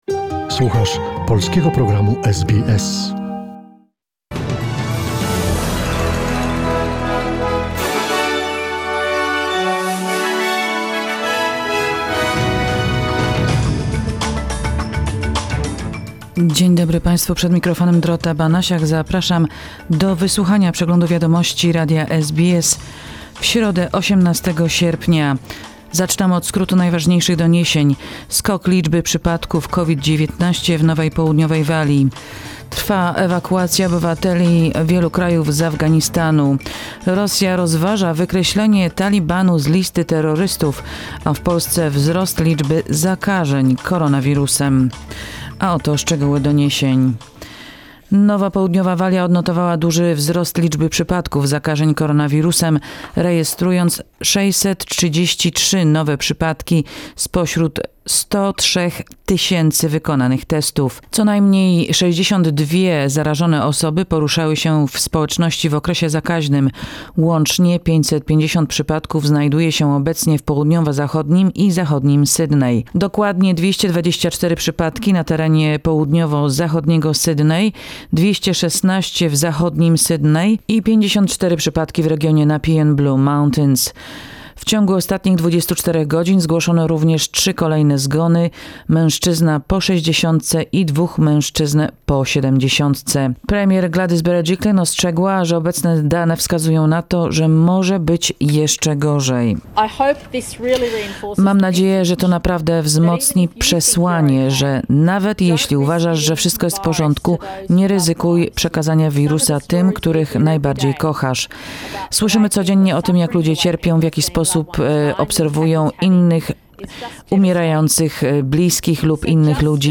SBS News in Polish, 18 August 2021